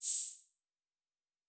pgs/Assets/Audio/Sci-Fi Sounds/MISC/Air Hiss 3_02.wav at master
Air Hiss 3_02.wav